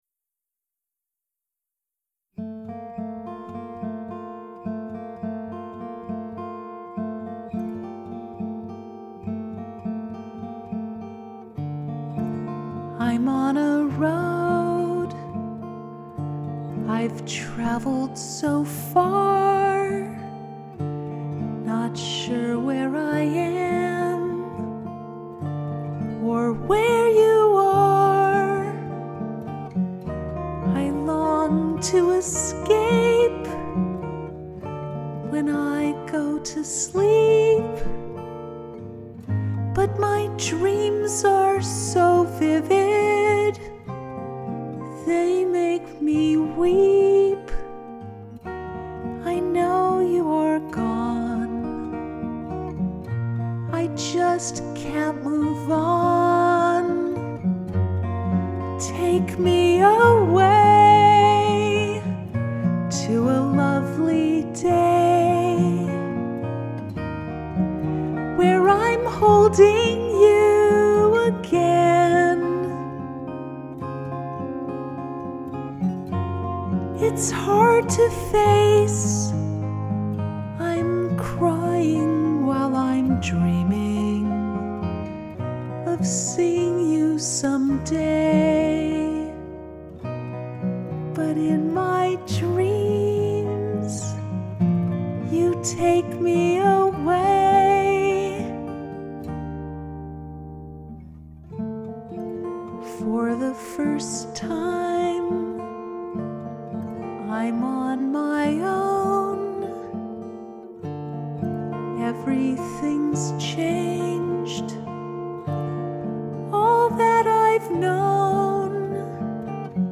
I recorded a new acoustic version of “Take Me Away” a few weeks ago.
Below is my latest vocal and piano/guitar recording:
It’s so rich and beautiful and you’ve added the piano so successfully.
Just sounds so soothing!
take-me-away-acoustic-6-16-19.mp3